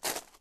added base steps sounds
ground_3.ogg